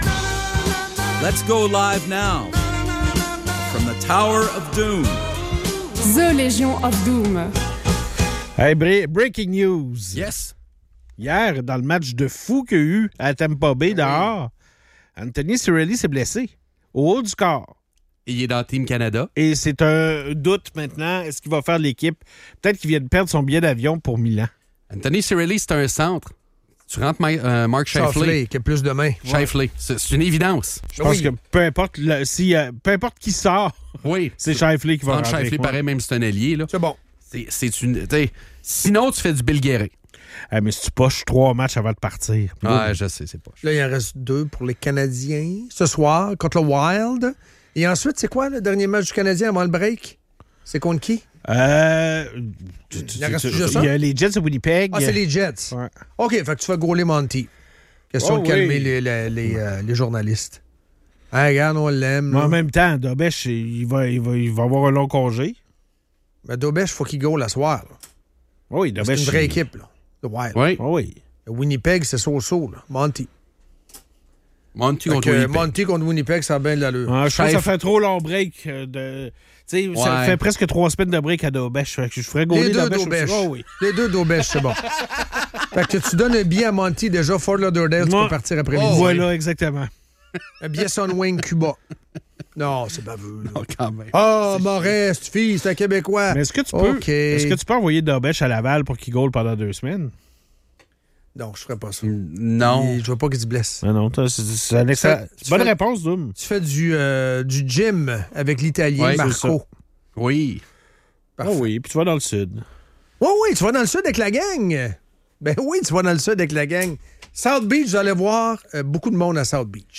La production de fraises en hiver au Québec est remise en question, révélant que ces fraises sont plus polluantes que celles importées de Californie. Malgré les investissements massifs du gouvernement dans des serres, les résultats sont décevants en raison de problèmes d'insectes et d'empreinte carbone élevée. Les animateurs soulignent l'ironie de vouloir être écoresponsables tout en utilisant des méthodes polluantes.